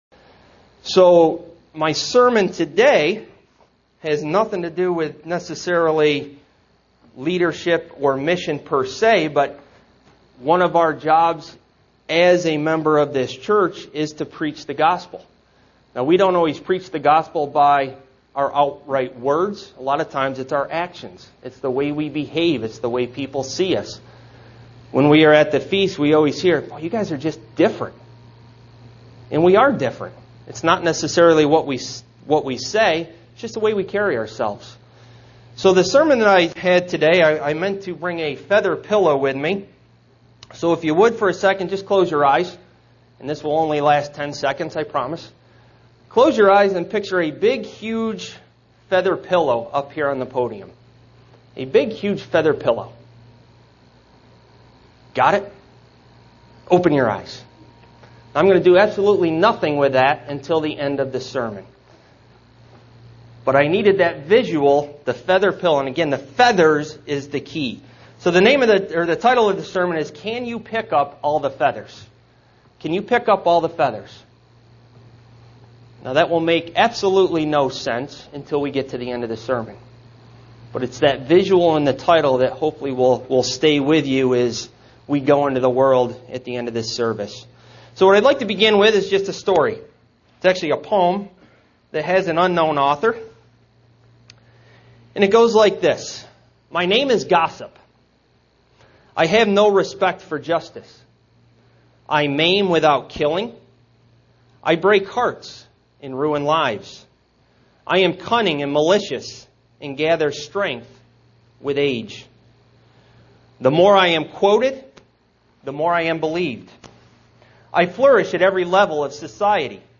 SEE VIDEO BELOW UCG Sermon Studying the bible?